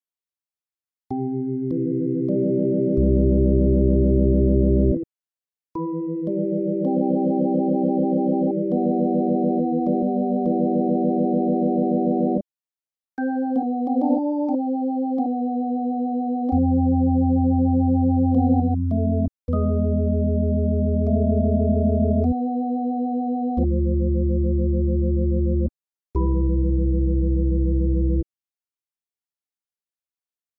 Блин, ну органы совсем космически звучат - отдавил уже всю клаву на маленьком нетбуке.
Вот какой-то NuBiPlus.dll сразу понравился, не Хаммонд, а вроде итальянский транзиписторный, надо погуглить, их оказывается тучу наделали в старые времена, а не только электромеханика с колесиками